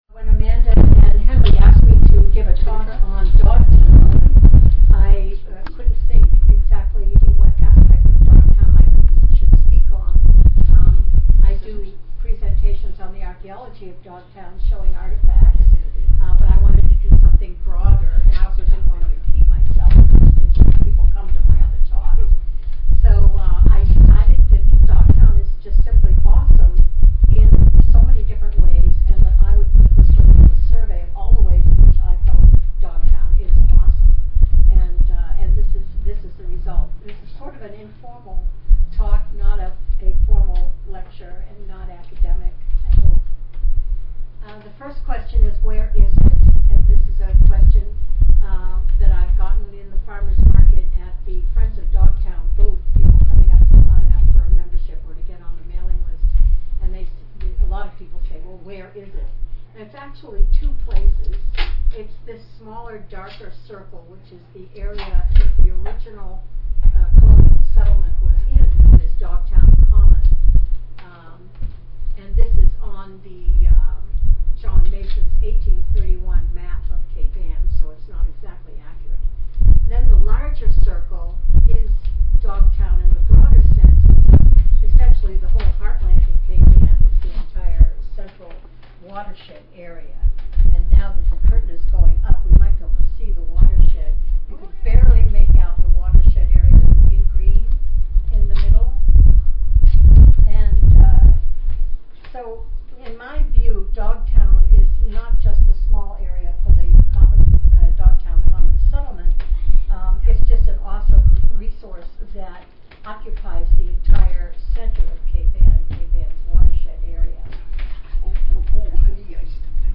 We had some slight sound difficulties in the beginning, but hang in there!